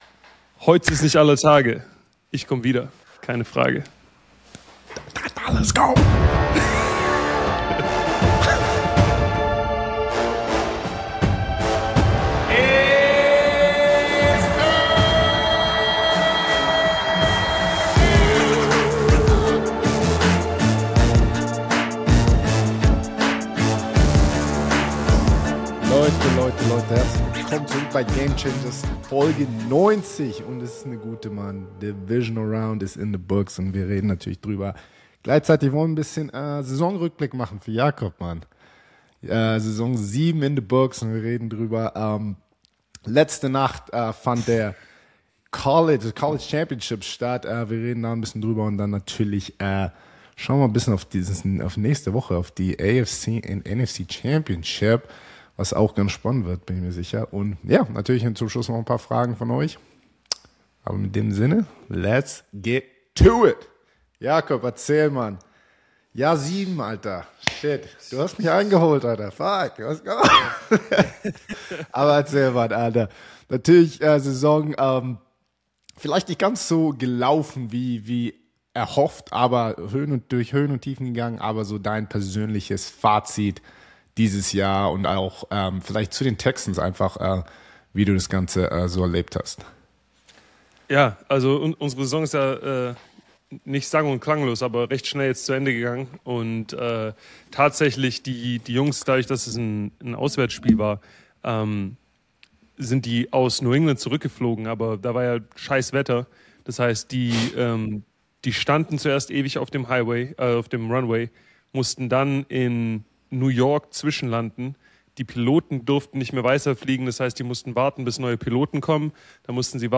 PS: Wir hatten Internetprobleme während der Produktion. Bitte entschuldigt den ein oder anderen Cut in dieser Folge.